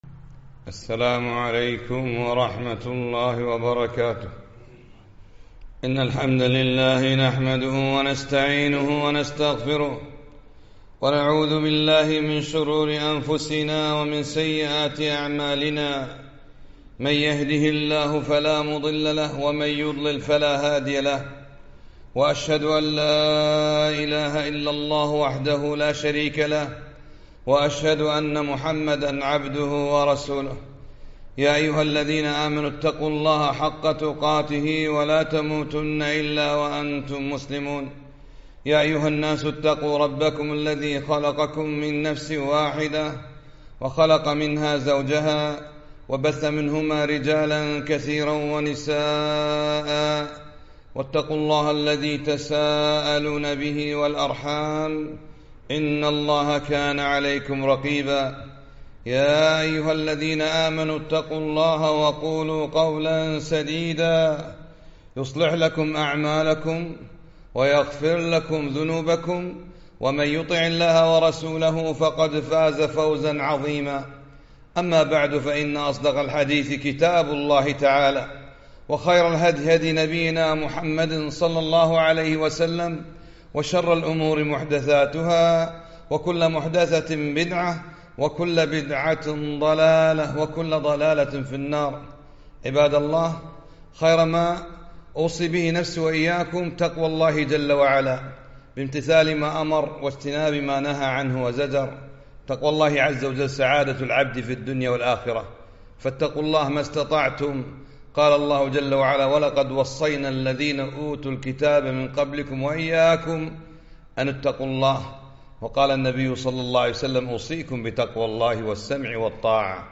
خطبة - قال الله تعالى ( إن الحسنات يذهبن السيئات)